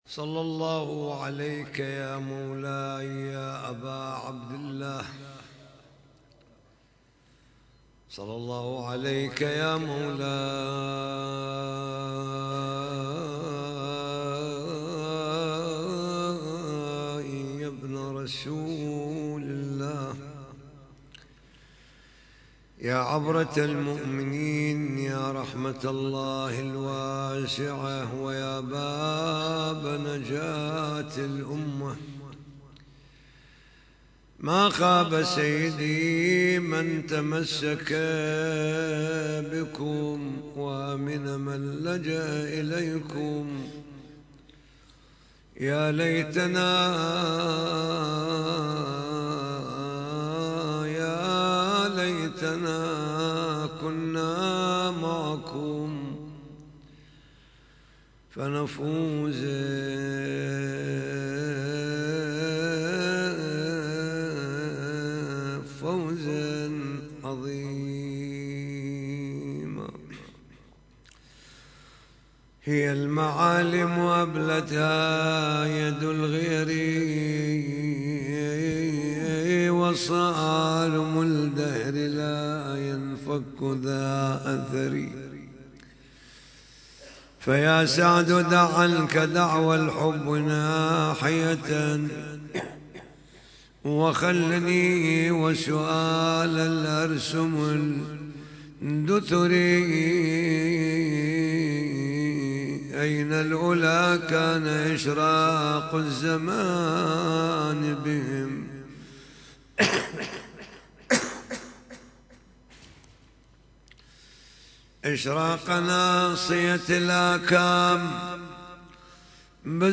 محاضرة ليلة 27 جمادى الأولى